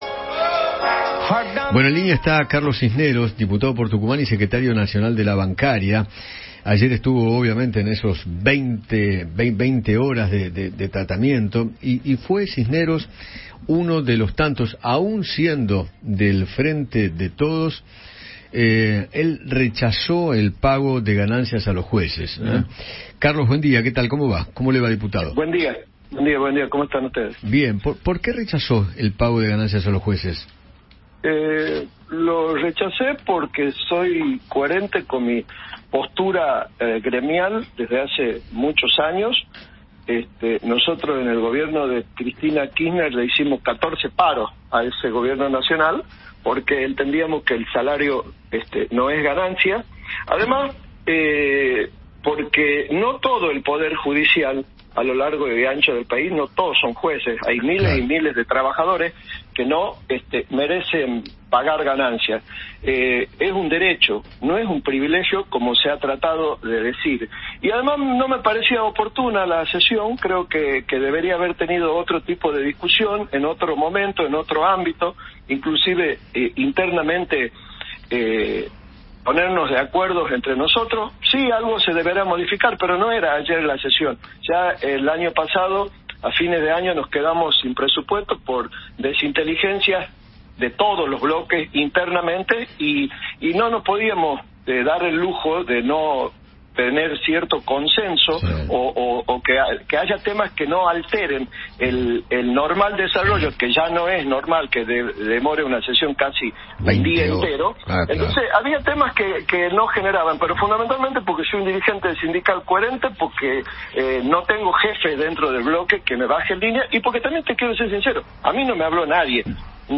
Carlos Cisneros, diputado de Tucumán por el Frente de Todos, conversó con Eduardo Feinmann acerca de su postura frente al impuesto a las ganancias para los empleados judiciales.